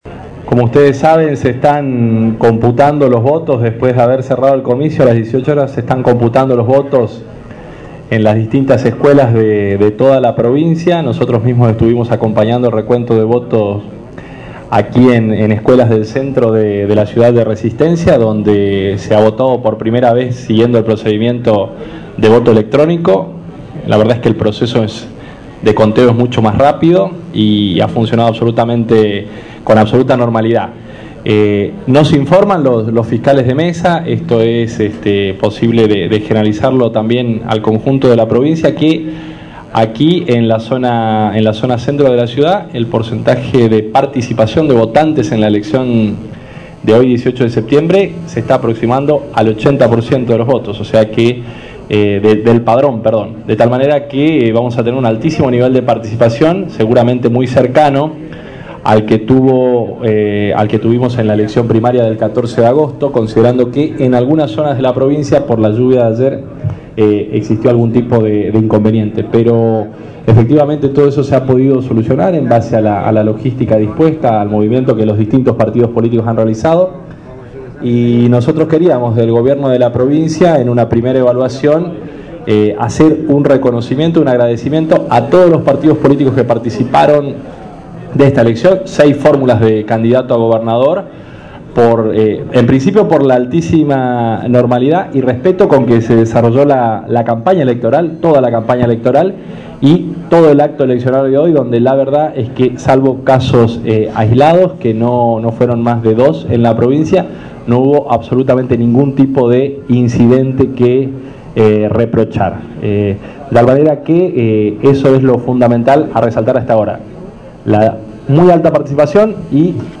18-09-11 Eduardo Aguilar 01 Ministro de Economia Cand 1er Dip Prov